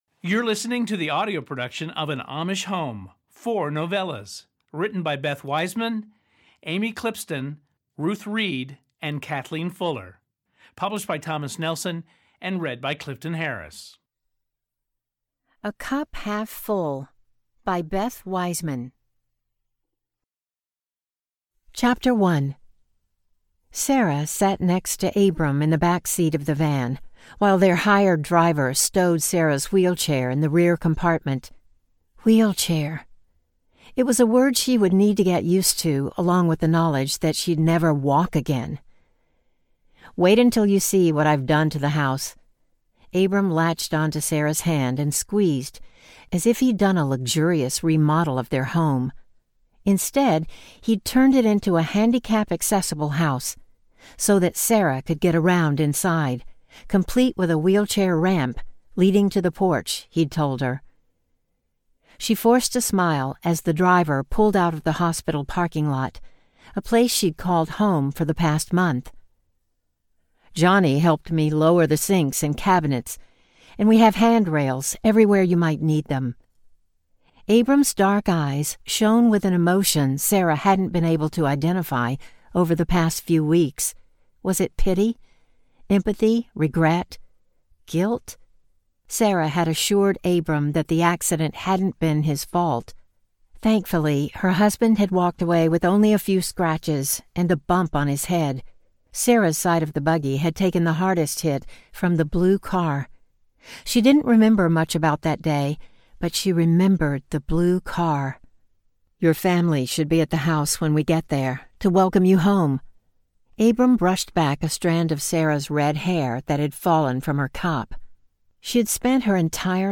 An Amish Home: Four Stories Audiobook